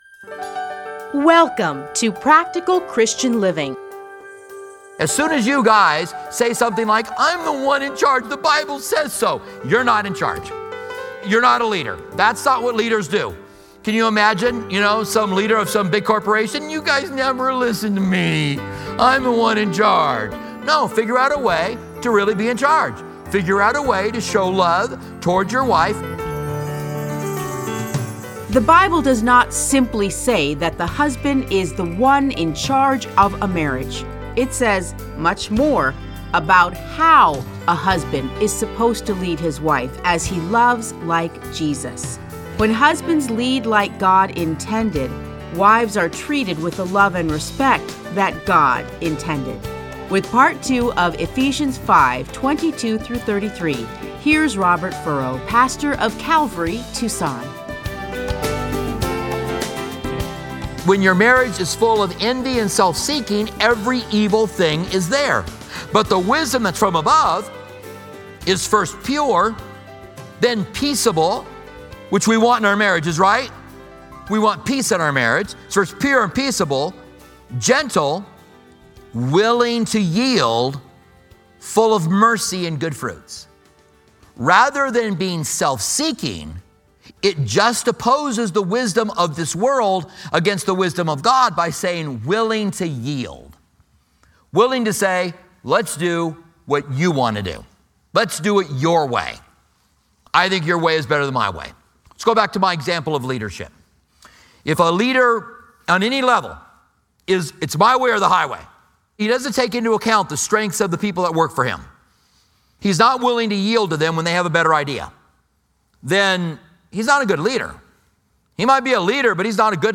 Listen to a teaching from Ephesians 5:22-33.